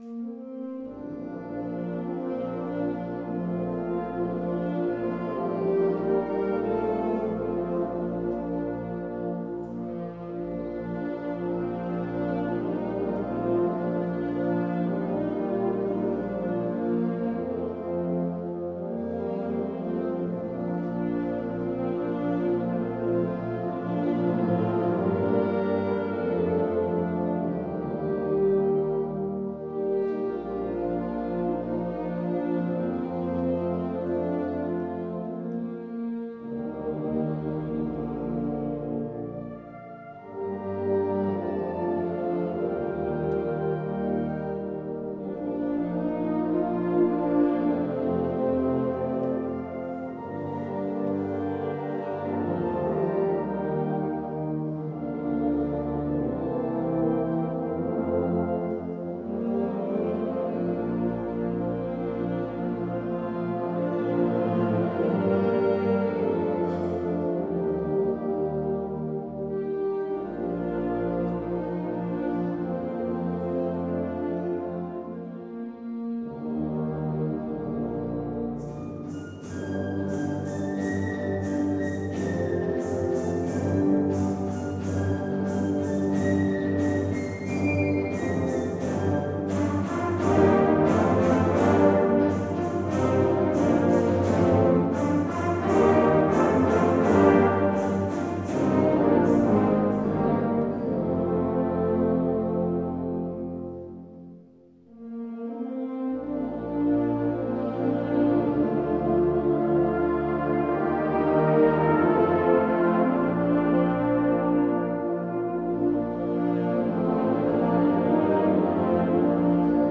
Kirchenkonzert 2024
Unter dem Motto "Musik zur Ehre Gottes" durften wir in der Pfarrkirche Untermieming ein Konzert darbieten.